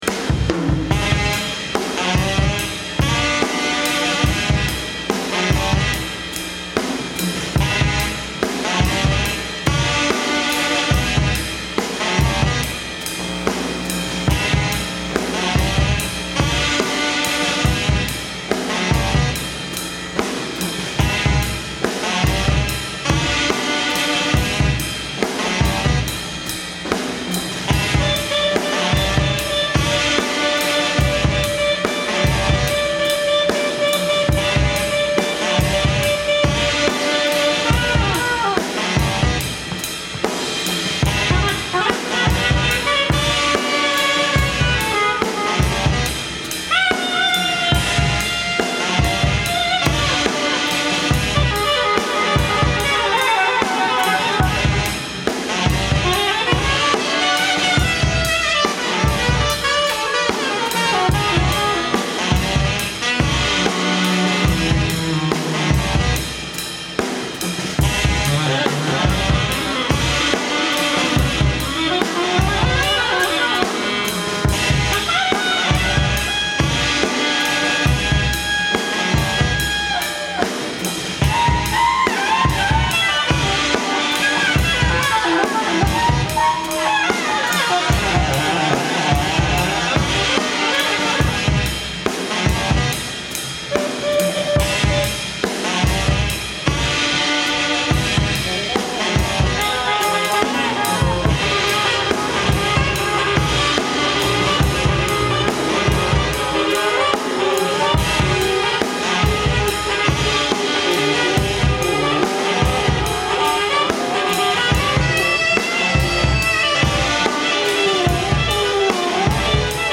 Recorded live at the 39th Street loft in Brooklyn.
alto saxophone, samplers
drums
Stereo